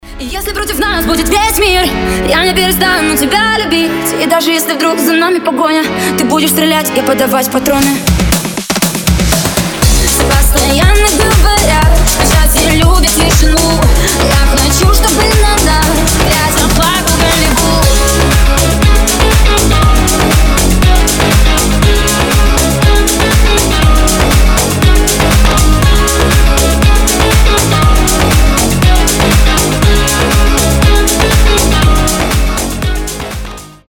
Клубные рингтоны
Club House remix